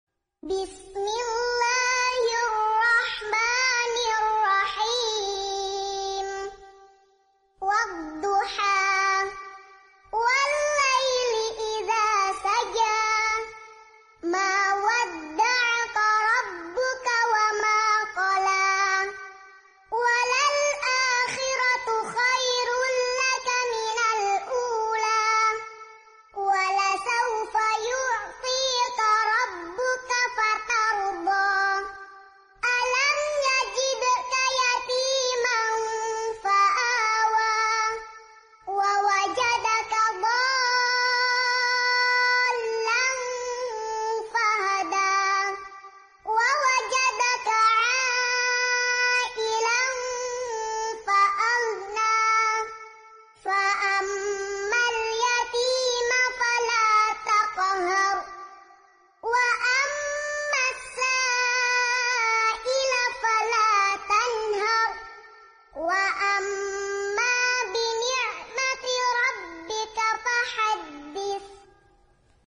Video murottal anak dibuat dari sebuah gambar hasil AI generator menggunakan HP android.